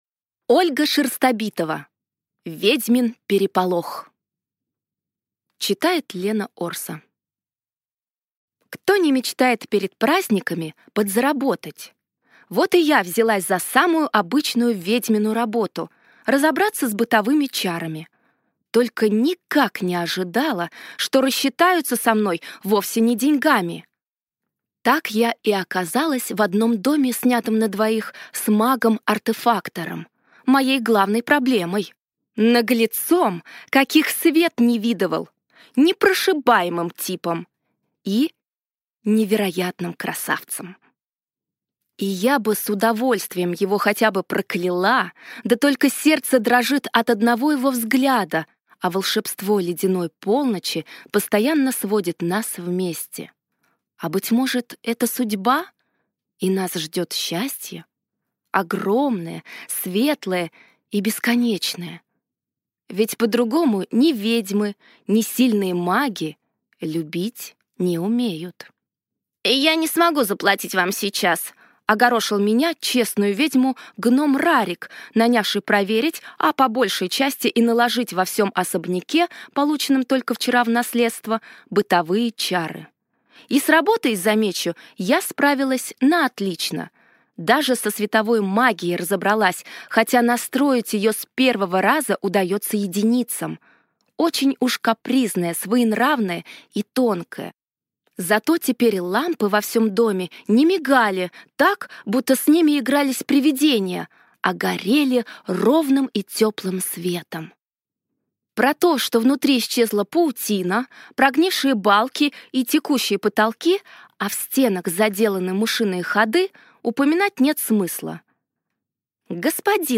Аудиокнига Ведьмин переполох | Библиотека аудиокниг